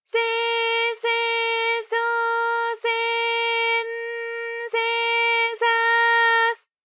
ALYS-DB-001-JPN - First Japanese UTAU vocal library of ALYS.
se_se_so_se_n_se_sa_s.wav